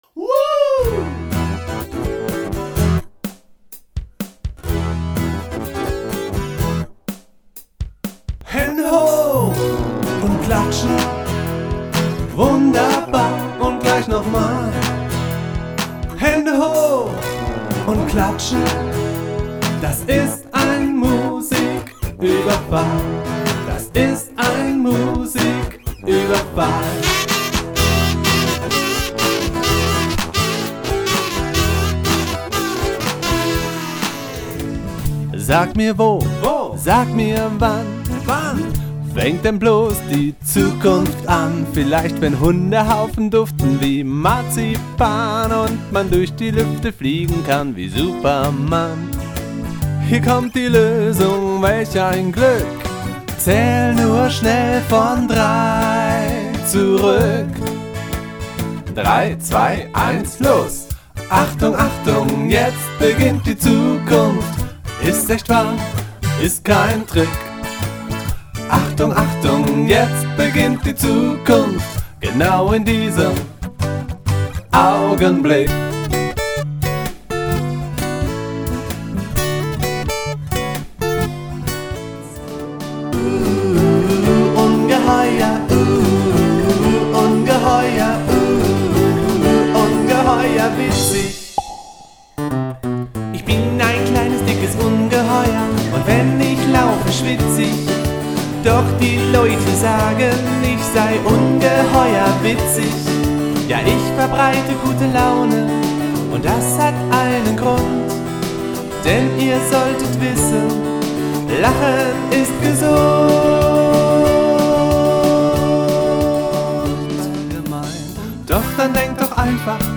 Einen Mix zum Reinhören gibt es hier trotzdem: